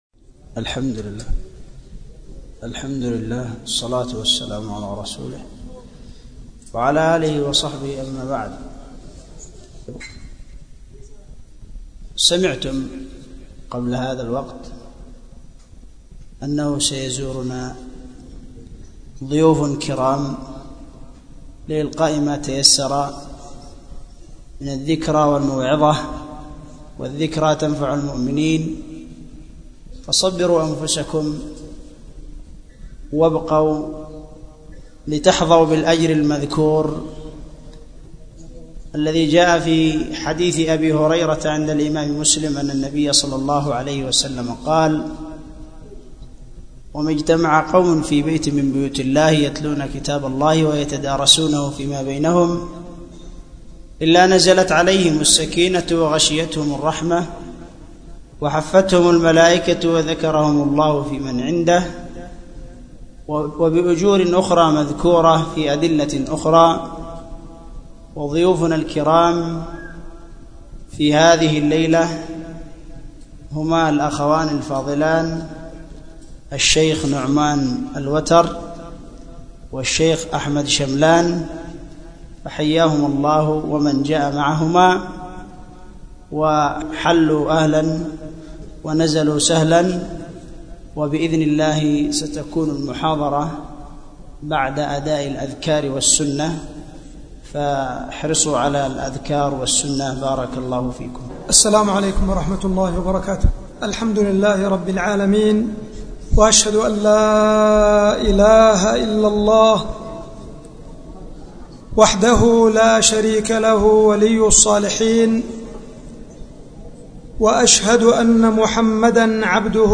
محاضرة بعنوان _ حفظ اللسان